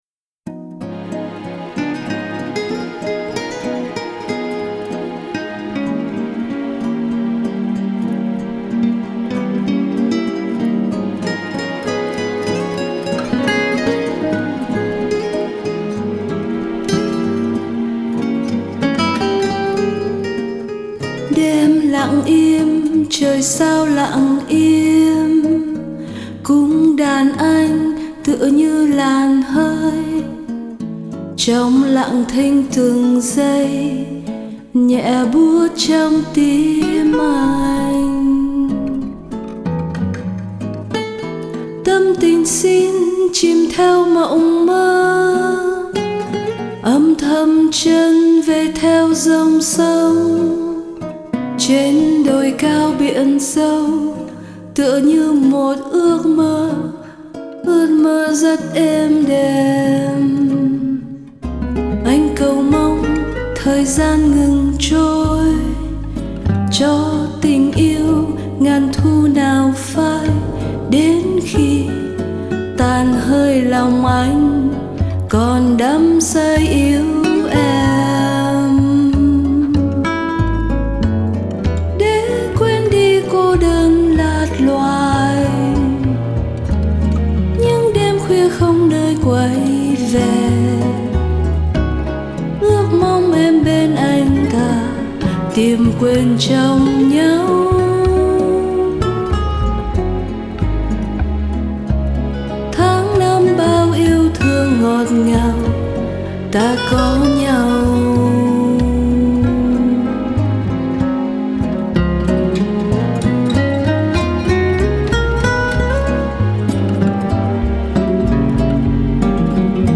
bossa nova